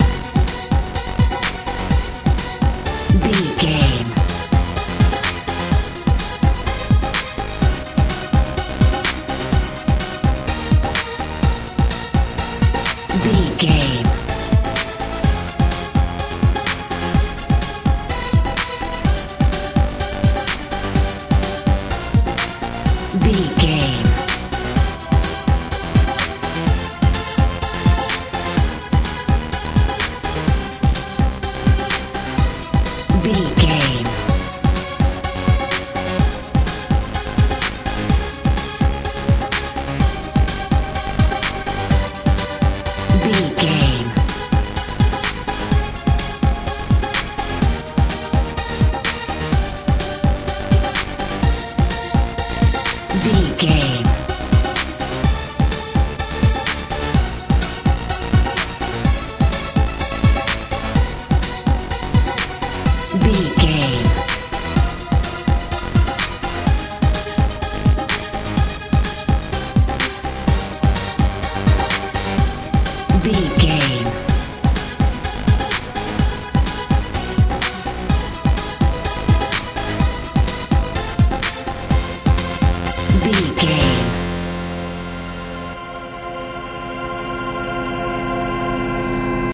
pop dance feel
Ionian/Major
hopeful
playful
synthesiser
bass guitar
drums
80s
90s
tension
suspense